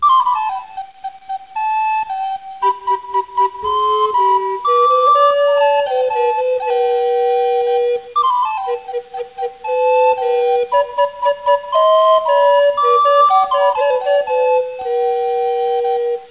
00860_Sound_static.mp3